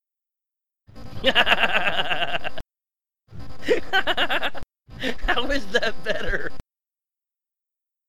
laugh 3